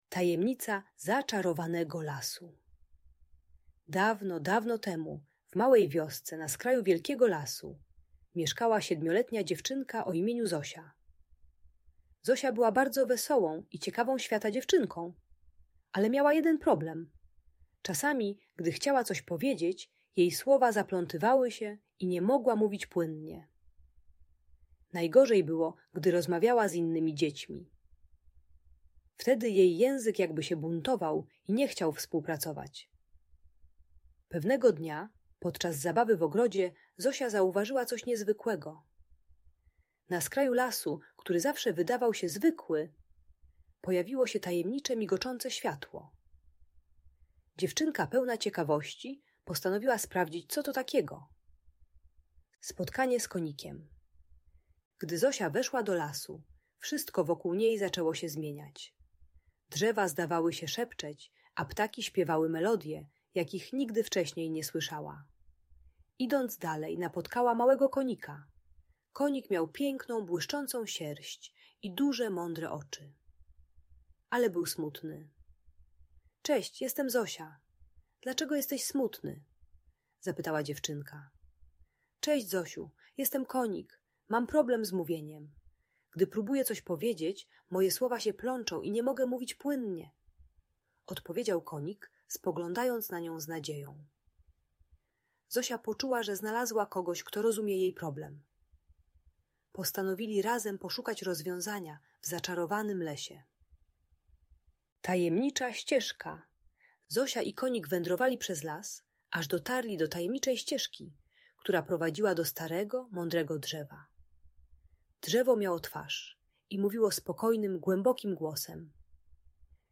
Tajemnica Zaczarowanego Lasu - Niepokojące zachowania | Audiobajka